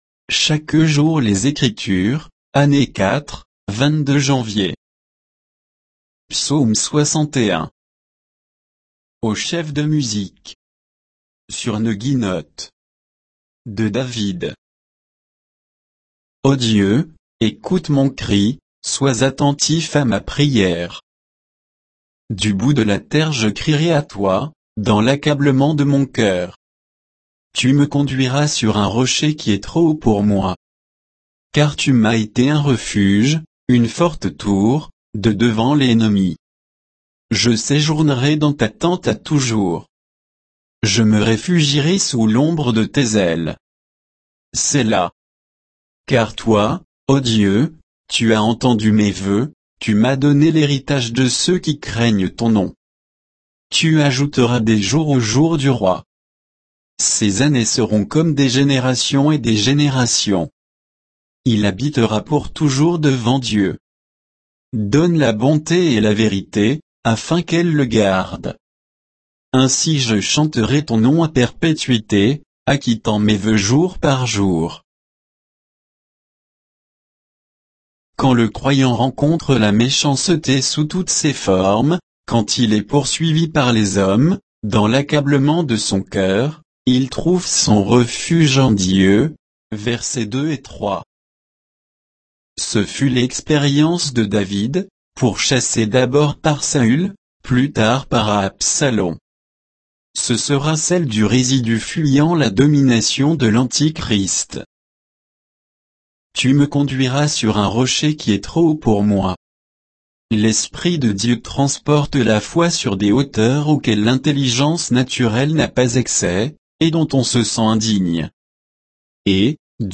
Méditation quoditienne de Chaque jour les Écritures sur Psaume 61